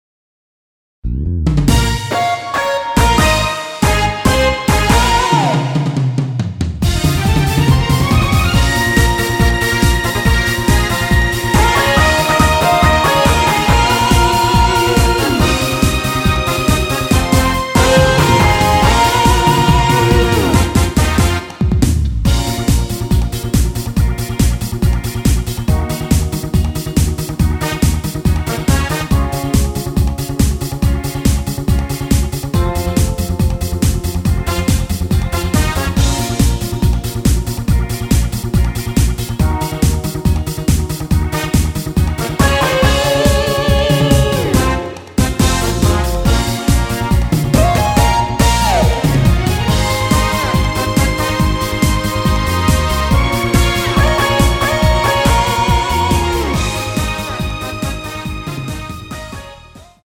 Bbm
앞부분30초, 뒷부분30초씩 편집해서 올려 드리고 있습니다.
중간에 음이 끈어지고 다시 나오는 이유는